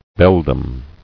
[bel·dam]